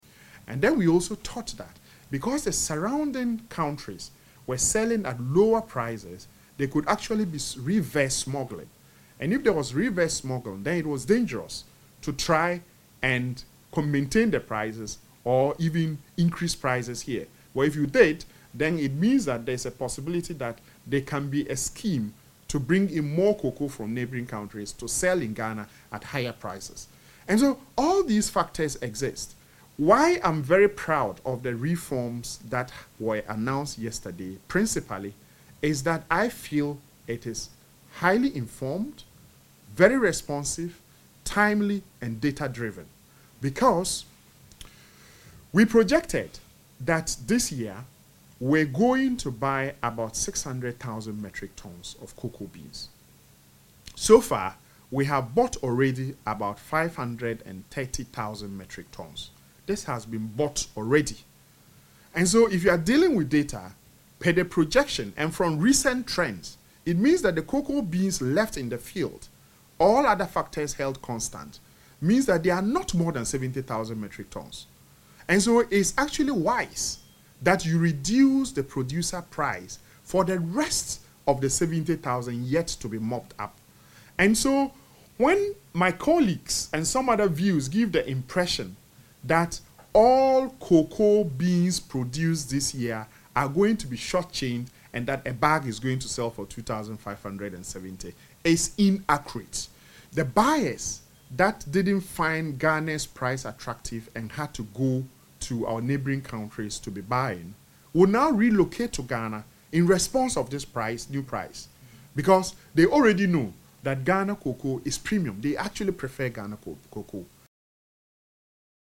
Addressing journalists, Chairman of the Food, Agriculture and Cocoa Affairs Committee, Godfred Seidu Jasaw, said the price adjustment was partly influenced by prevailing rates in neighbouring cocoa-producing countries to prevent reverse smuggling into Ghana.